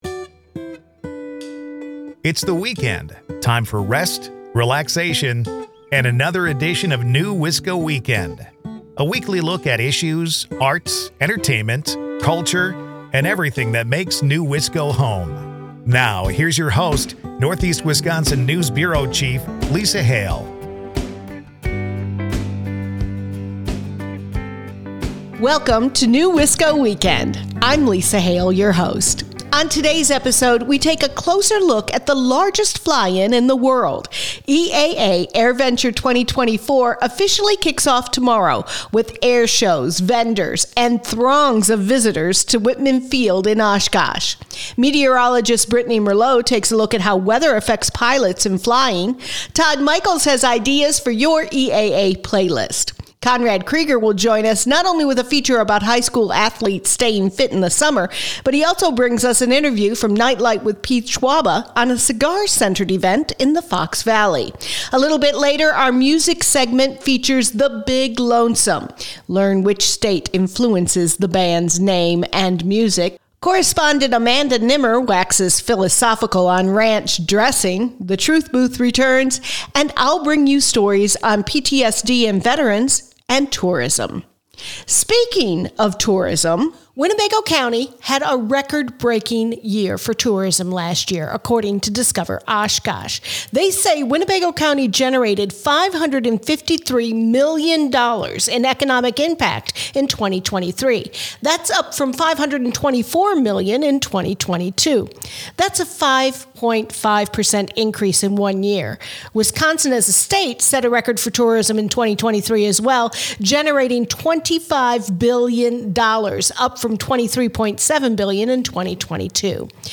The Big Lonesome is our musical guest.
NEWisco Weekend is a news magazine filled with conversations and stories about issues, stories, entertainment, and culture that make the Fox Valley and Green Bay Area a rich, unique area to call home.